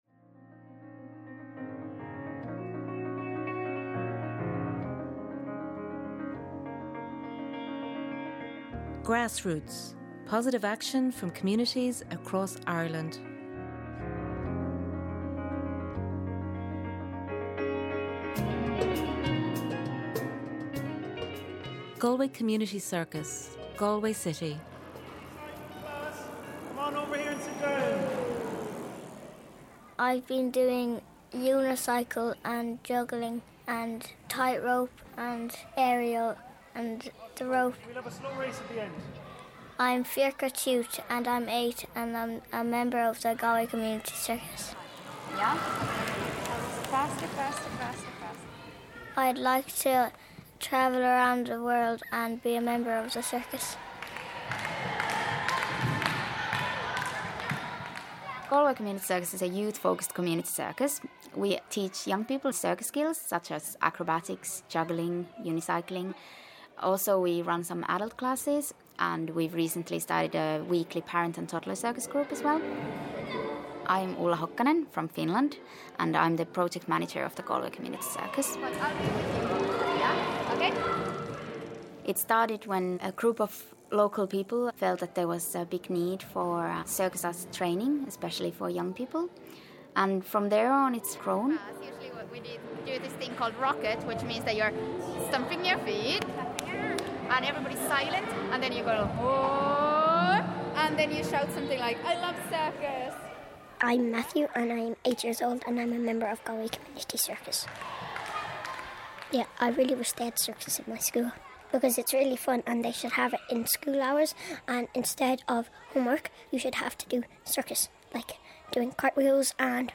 This is the first part of the documentary series Grassroots, It’s an Athena Media production produced for Newstalk by Athena Media and made with the support of the Broadcasting Support of Ireland.